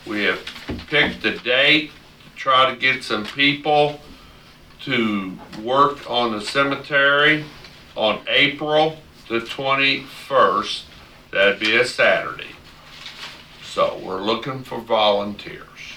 At the meeting of the Saline County Commission on Tuesday, March 13, Presiding Commissioner Kile Guthrey Jr. said the Saline County Cemetery Fund needs donations of money, manpower and equipment.